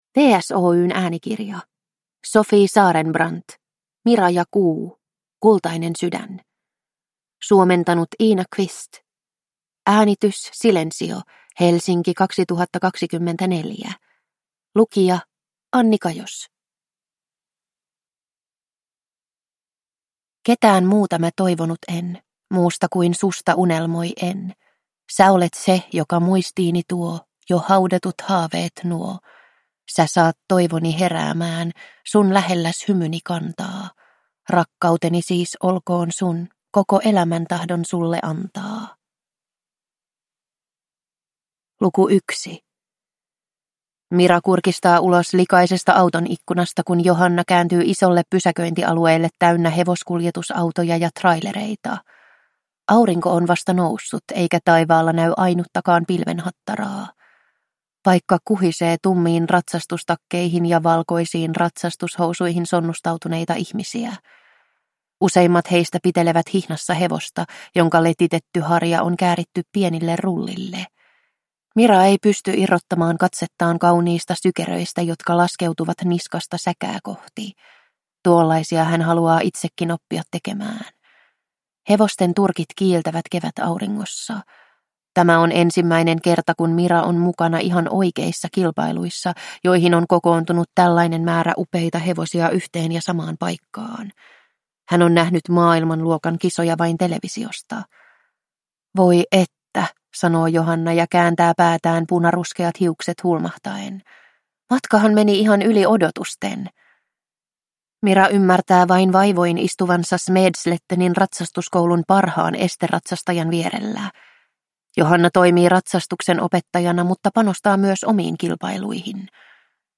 Mira ja Kuu: Kultainen sydän – Ljudbok